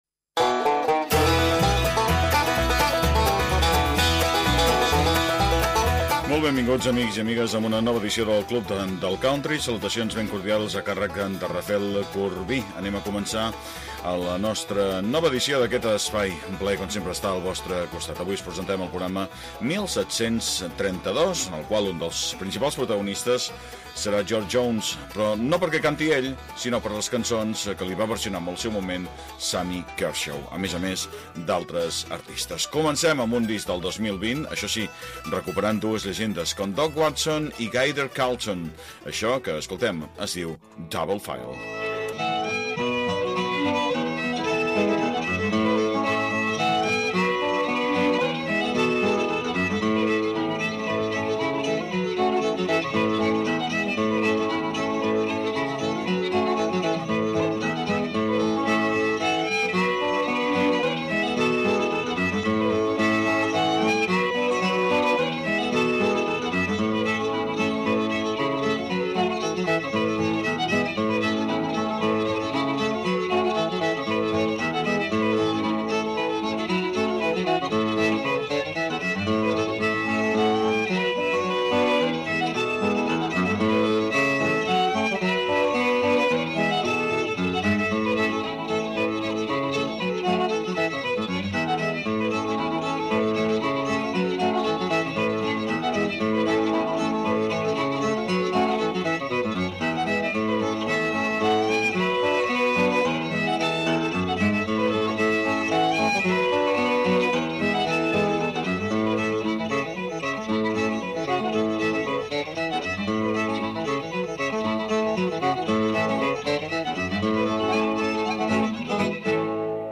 El club del country. Programa de música country. Durant 60 minuts escoltaràs els èxits del moment i els grans clàssics de la música country.